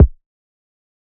TS - KICK (13).wav